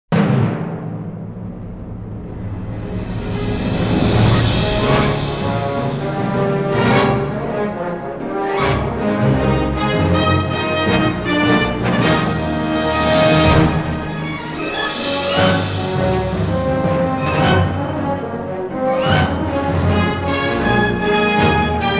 саундтрек